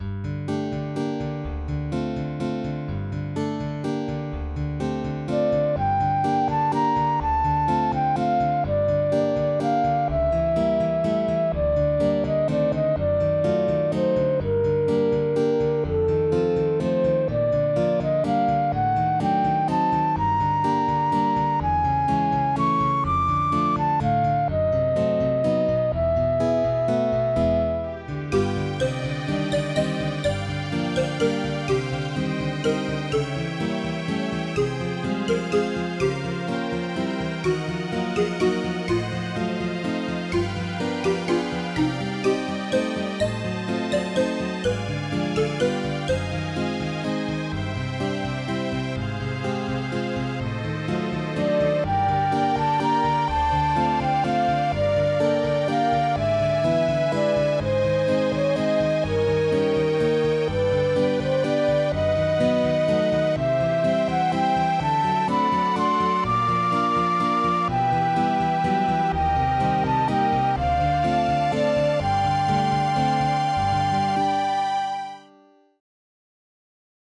Style: Instrumental